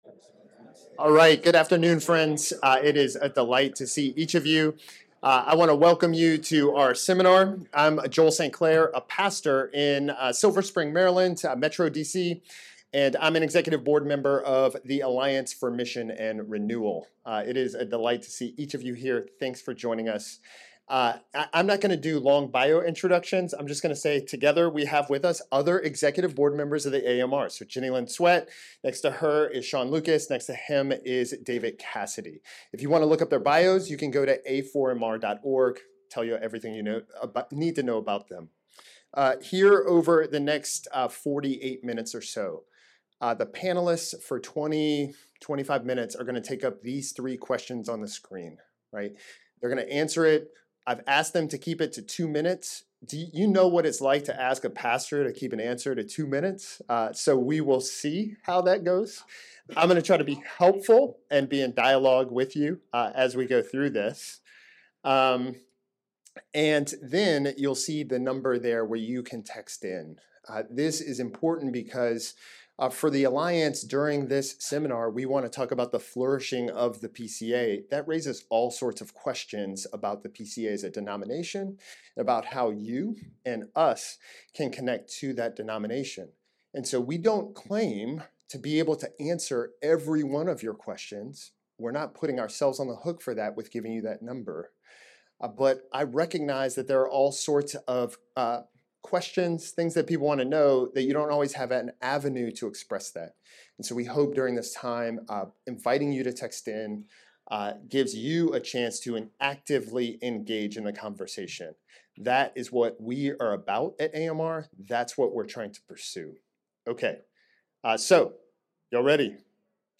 Highlights from the Seminar Discussion
At this year’s PCA General Assembly, the Alliance for Mission and Renewal (AMR) hosted a candid and hopeful conversation on the state of the denomination.